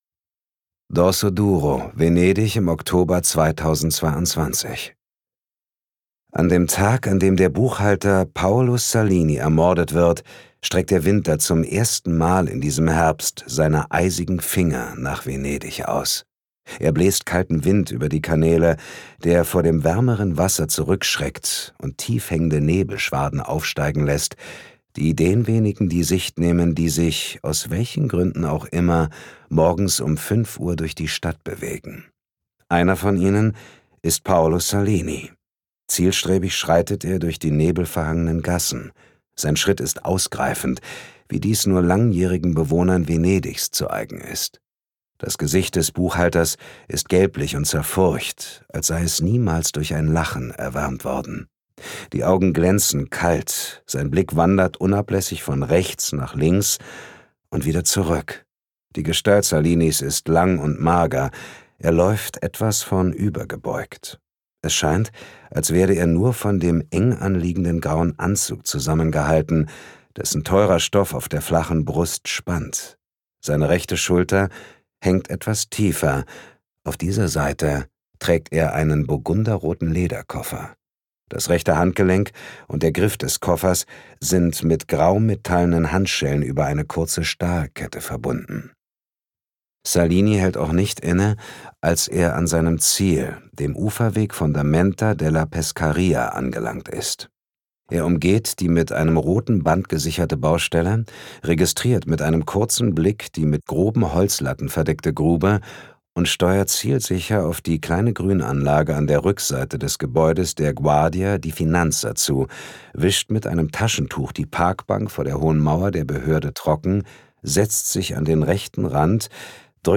Falsche Freunde Commissario Morello ermittelt in Venedig Wolfgang Schorlau , Claudio Caiolo (Autoren) Dietmar Wunder (Sprecher) Audio-CD 2023 | 1.